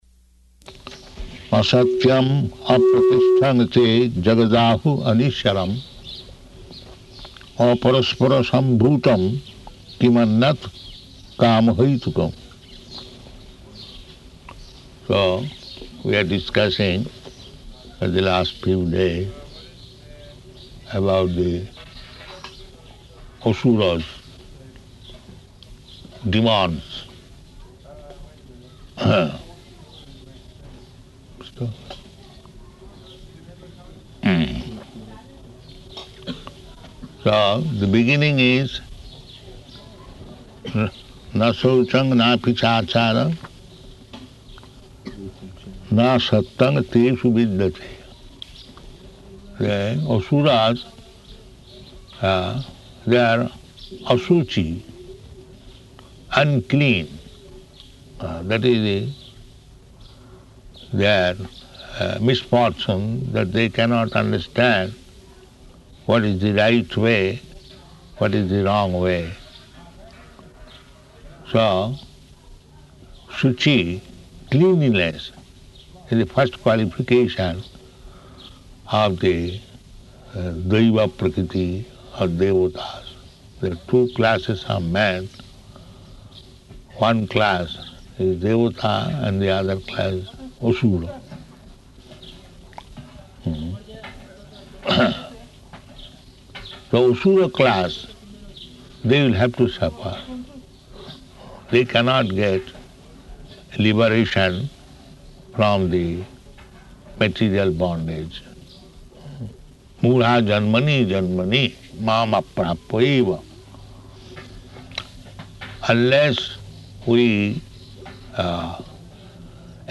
Type: Bhagavad-gita
Location: Hyderabad